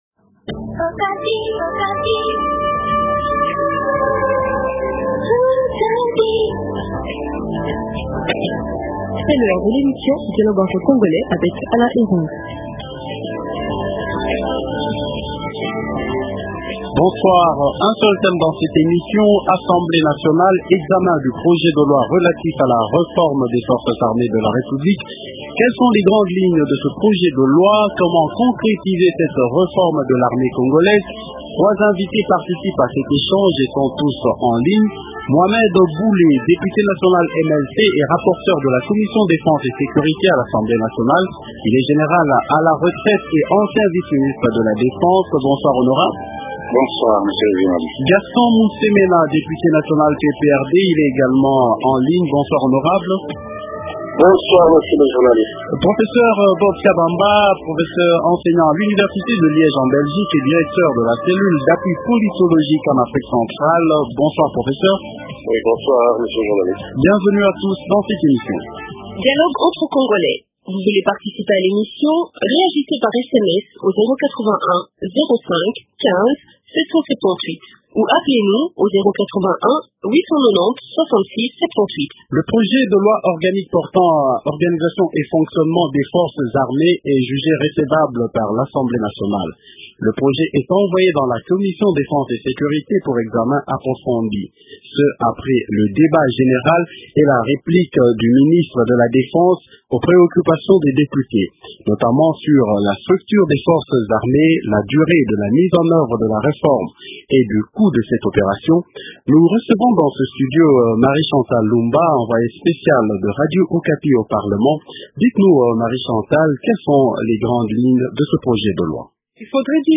Invité -Mouhamed Bule, Député national Mlc et rapporteur de la commission défense et sécurité à l’Assemblée nationale.